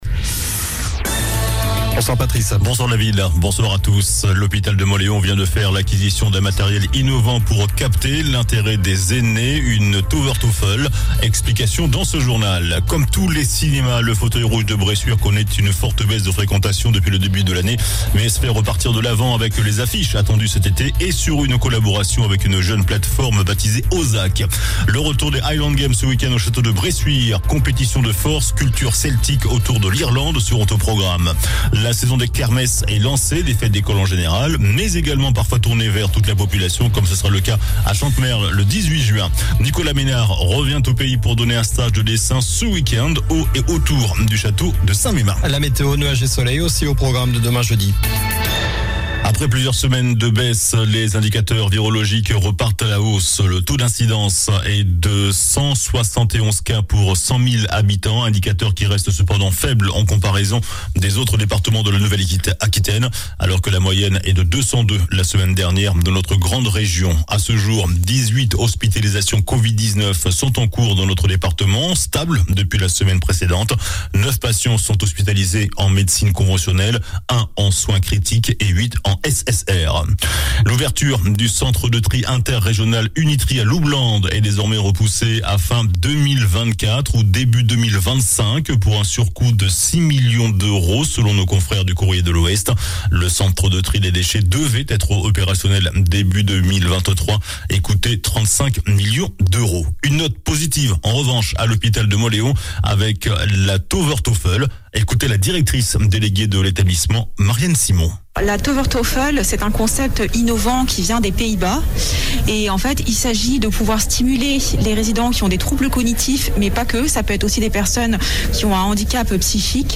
JOURNAL DU MERCREDI 08 JUIN ( SOIR )